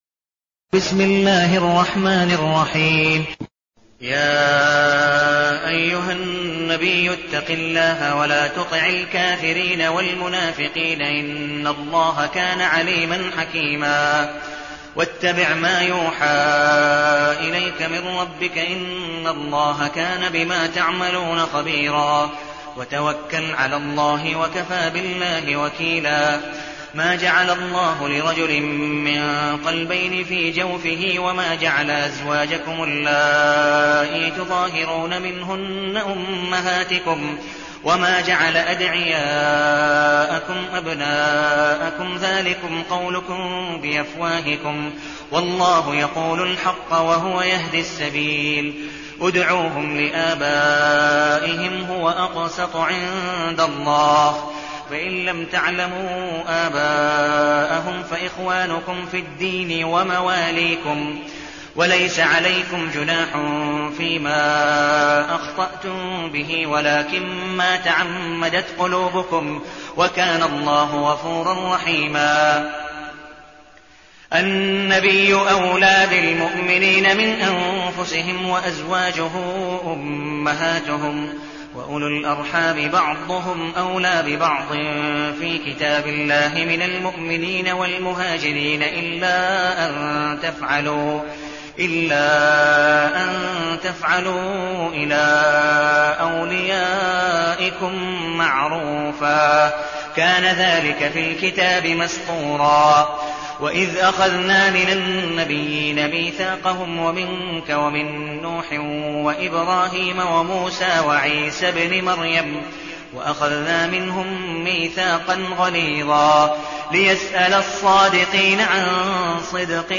المكان: المسجد النبوي الشيخ: عبدالودود بن مقبول حنيف عبدالودود بن مقبول حنيف الأحزاب